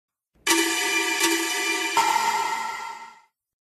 Anime Scary News Sound Effect - Bouton d'effet sonore